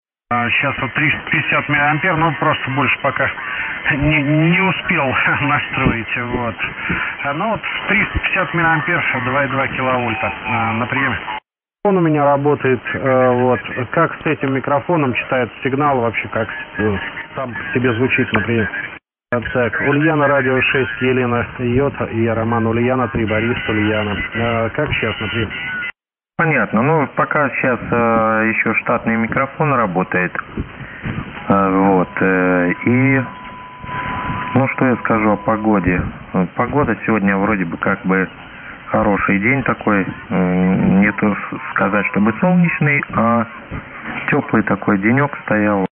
Есть знакомые голоса радиолюбителей, которых, к сожалению, уже нет с нами...
Обратите внимание на полосу приема, всего лишь 3,2кГц.